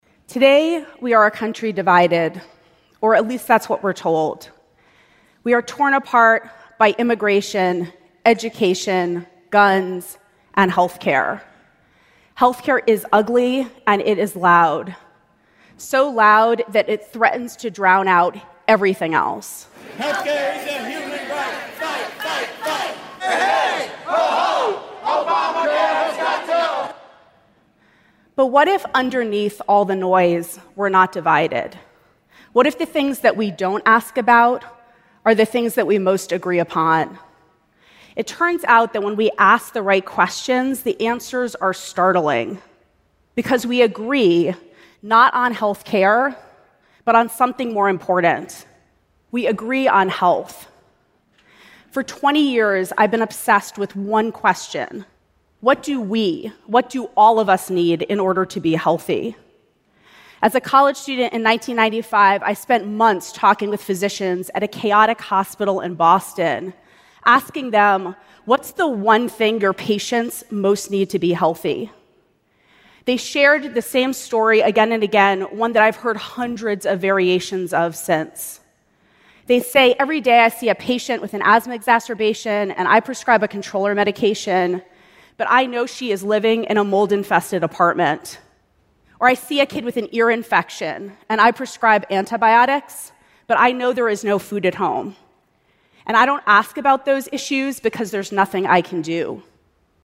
TED演讲:美国人在健康问题上的共识是什么(1) 听力文件下载—在线英语听力室